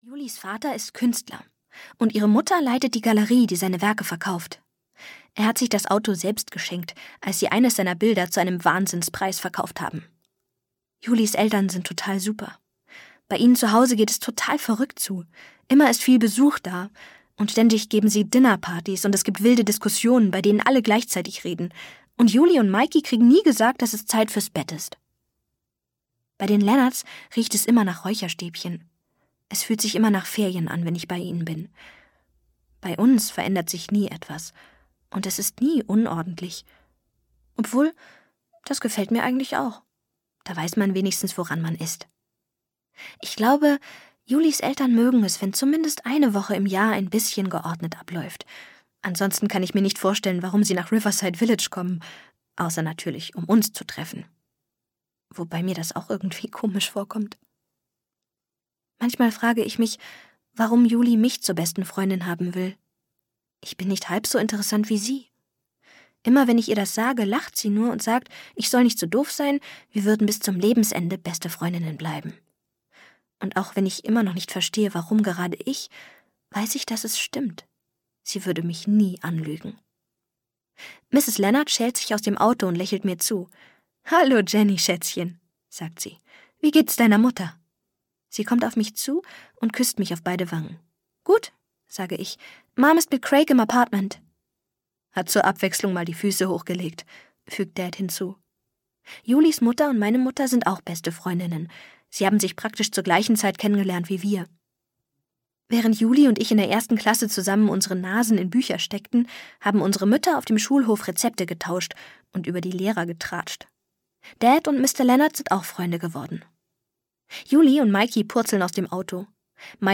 Ein Jahr ohne Juli - Liz Kessler - Hörbuch